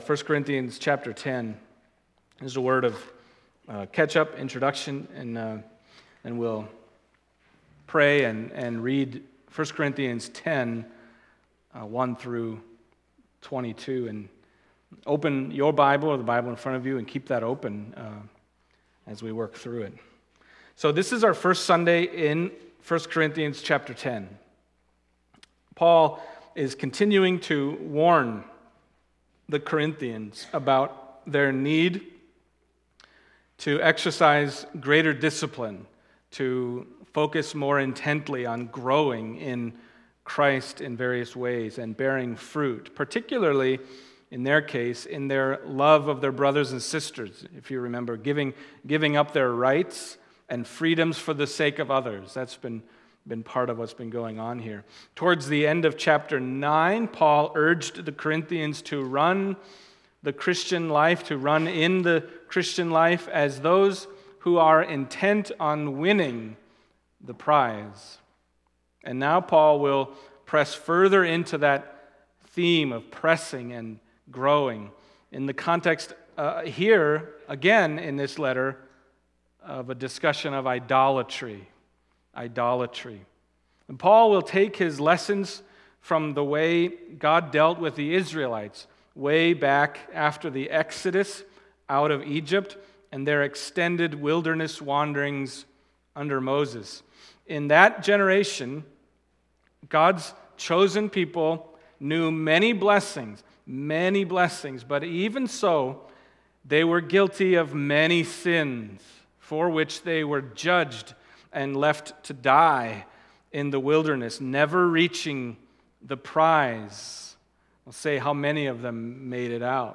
Passage: 1 Corinthians 10:1-22 Service Type: Sunday Morning